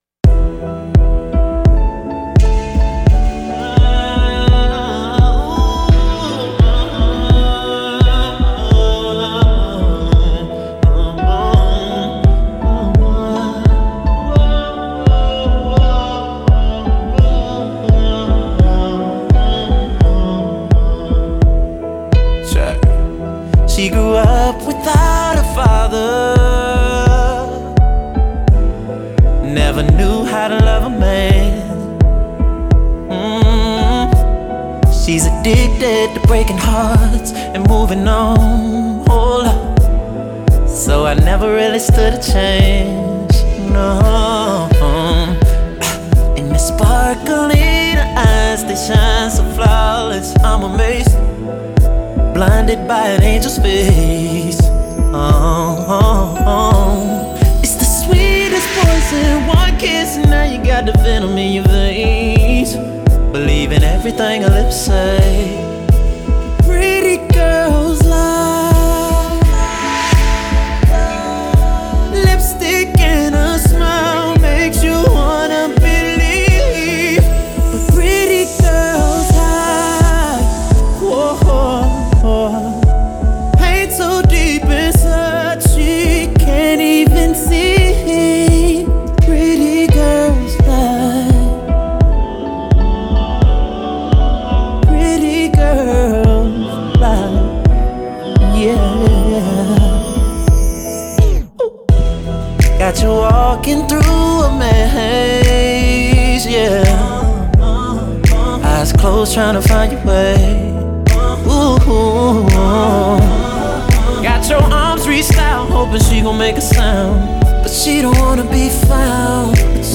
this one he slows it down and gives you classic